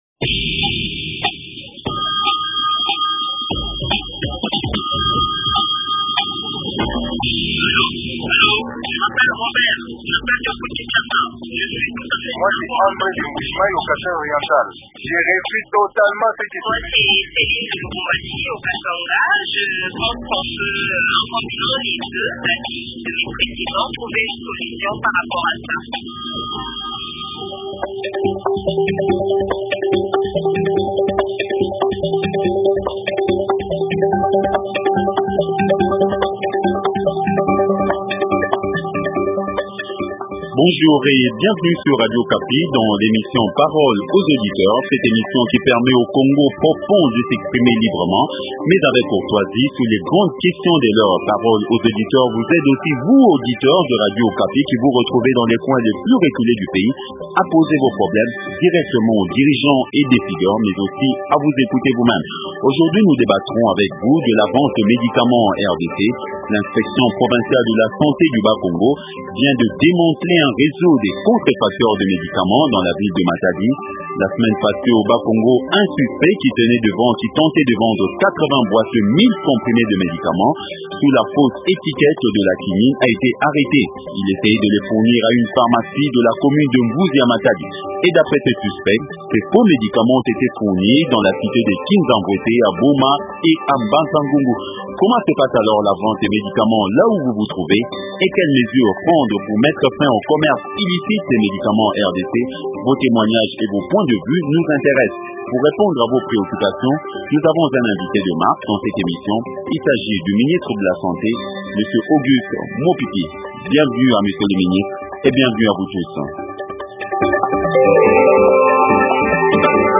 Invité: Auguste Mopipi, ministre de la santé.